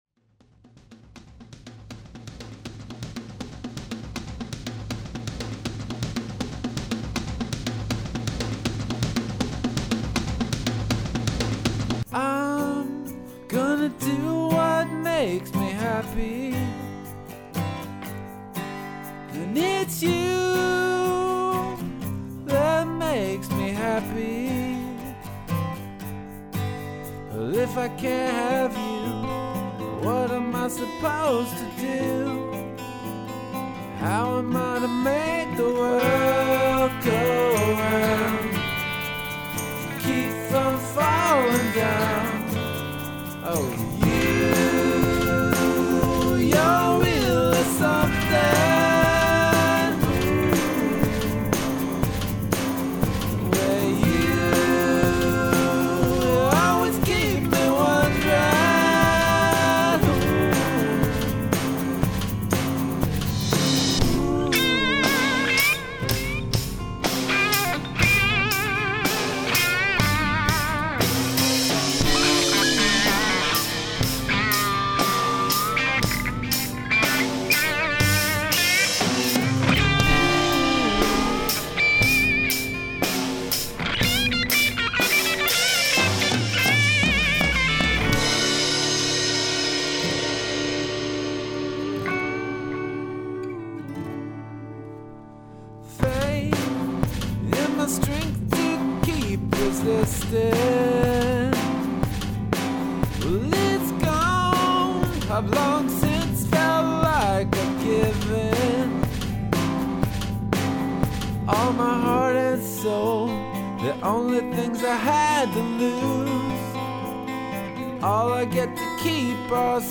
We have a new hot young guitar player.
P.S. New demos for your listening pleasure: